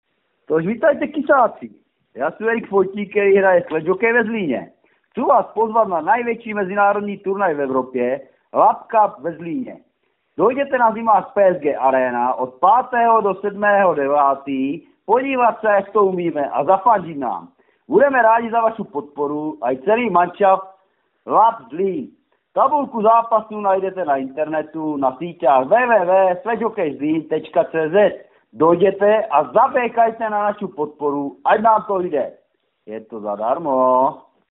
Audio pozvánky na mezinárodní turnaj ve sledge hokeji LAPP CUP ZLÍN 2013. Které namluvili tři hráči zlínského týmu SHK Lapp Zlín. Pro zlínské rádio Kiss Publikum.